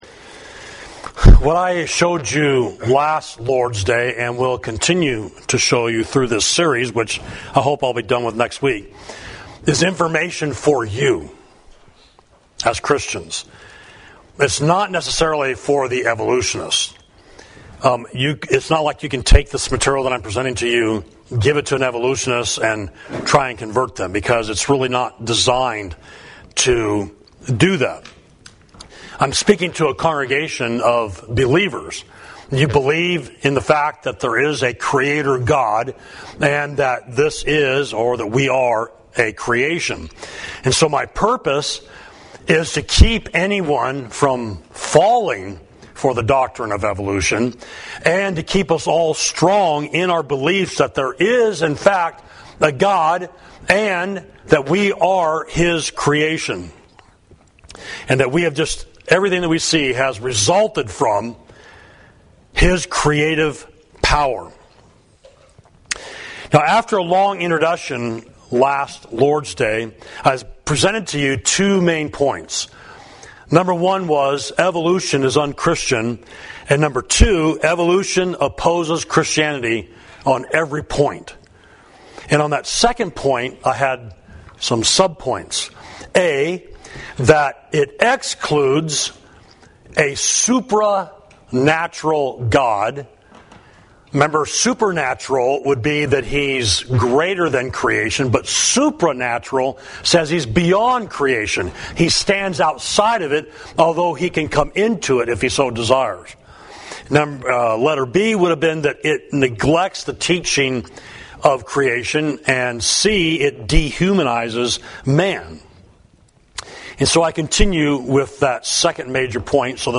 Sermon: Disproving Evolution, Part 2 – Savage Street Church of Christ